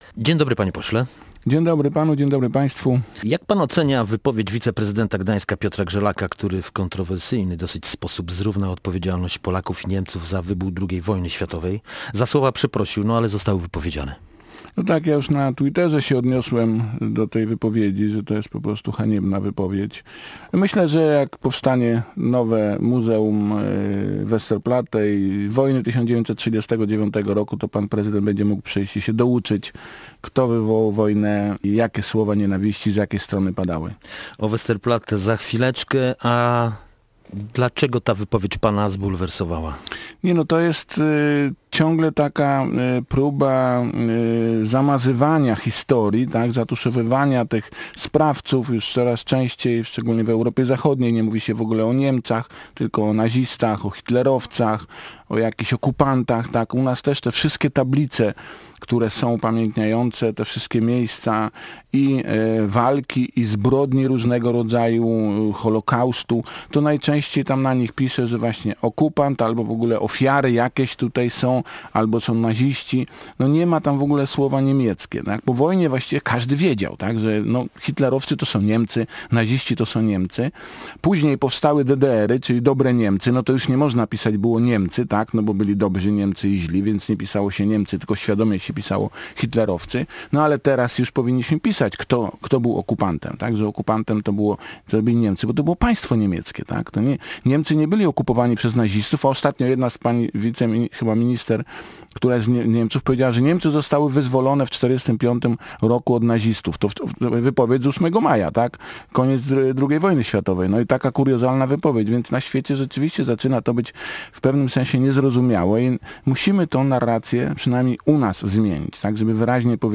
Gościem Dnia Radia Gdańsk był Kazimierz Smoliński, poseł Prawa i Sprawiedliwości, jeden z inicjatorów projektu ustawy dotyczącej budowy Muzeum Westerplatte i Wojny 1939.